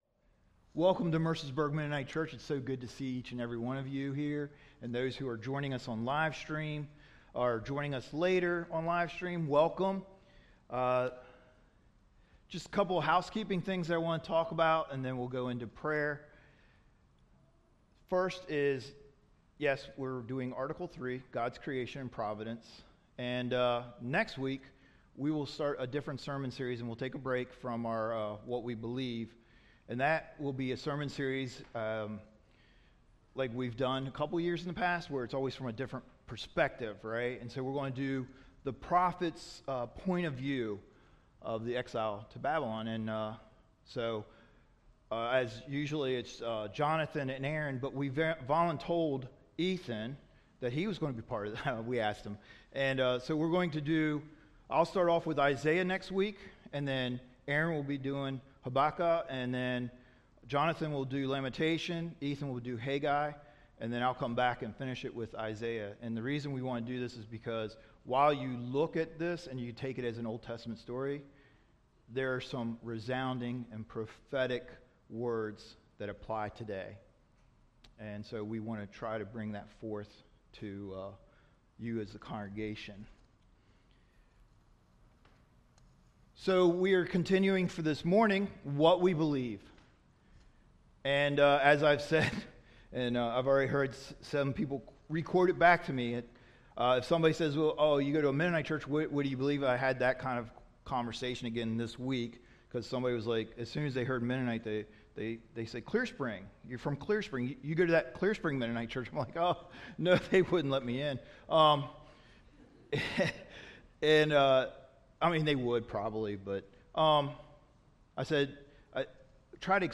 whose image do you think about? 2 Corinthians 5:16-17 Image is Everything Image of God Tagged with 2nd Service Audio